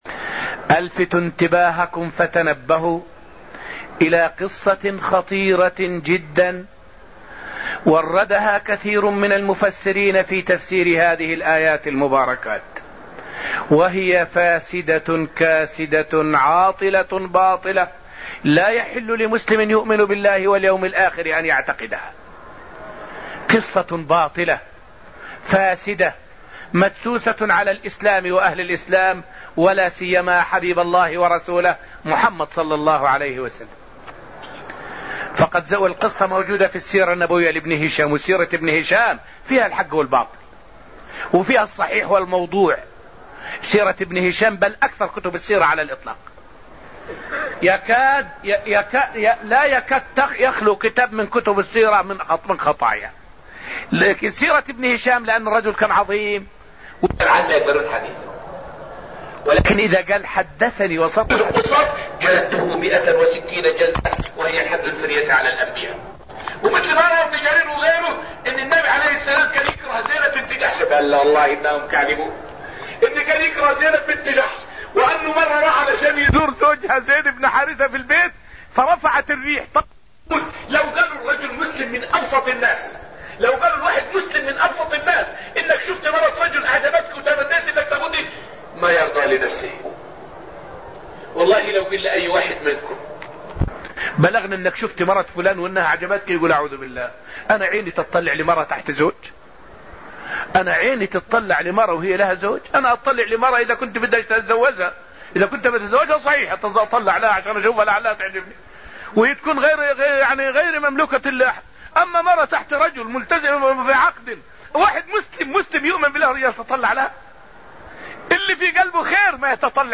فائدة من الدرس السادس من دروس تفسير سورة النجم والتي ألقيت في المسجد النبوي الشريف حول قصة فاسدة أوردها بعض المفسرين في كتبهم والجواب عليها.